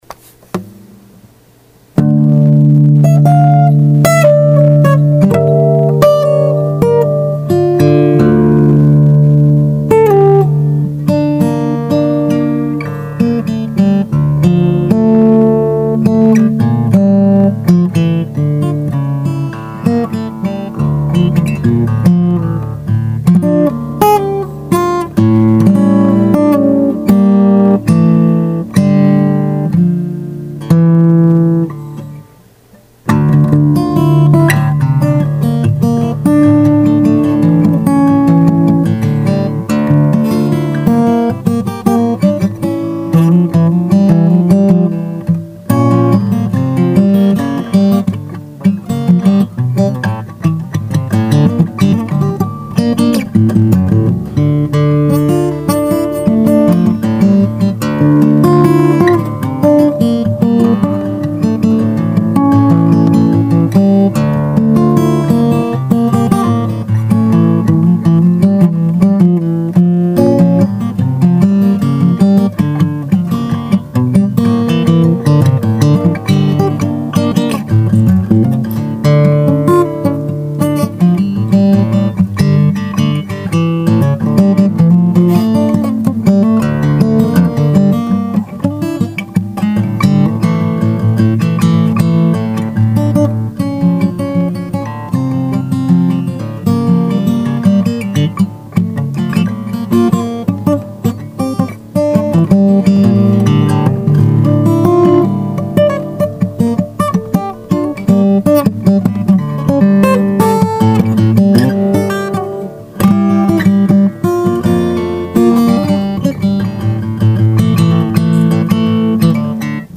試しに弾いてみました
チューニングは６弦からDADGB♭Eです。